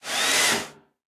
driller.ogg